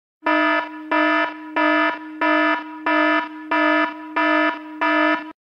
دانلود صدای آژیر خطر 1 از ساعد نیوز با لینک مستقیم و کیفیت بالا
جلوه های صوتی